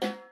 Percs